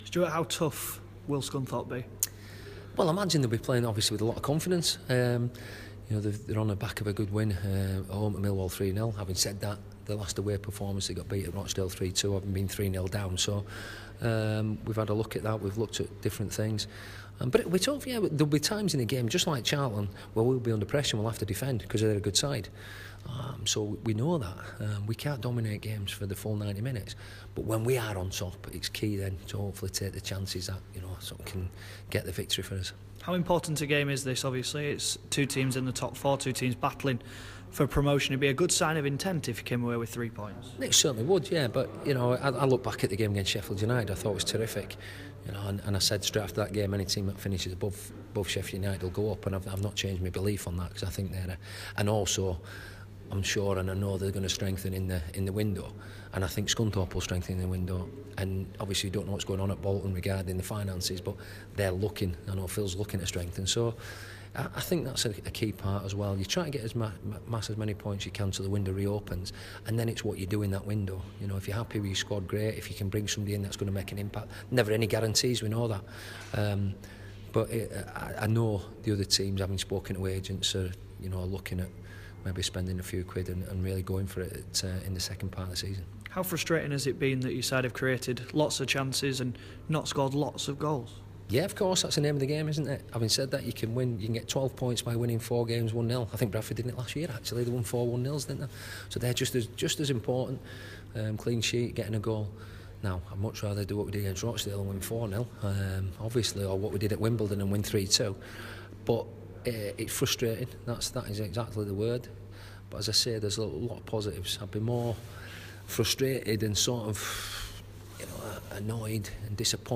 Bradford City boss Stuart McCall previews Boxing Day clash with Scunthorpe and talks potential new recruits